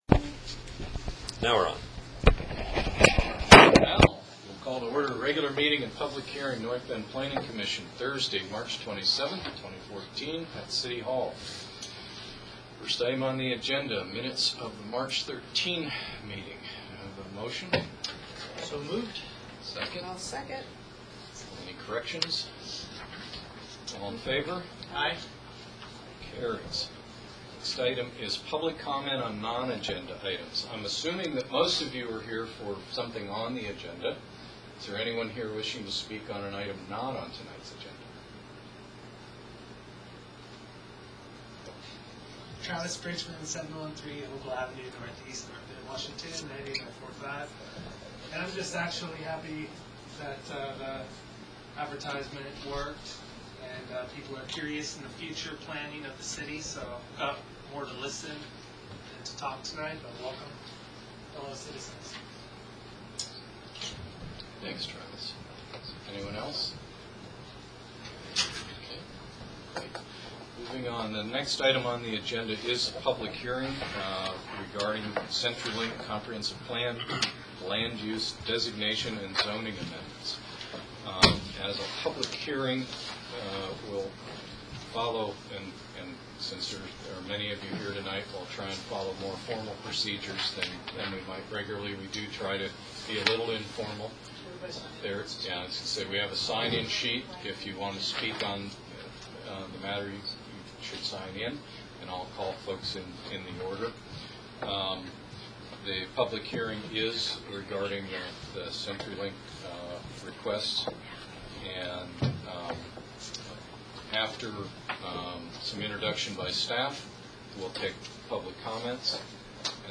Planning Commission Audio - March 27, 2014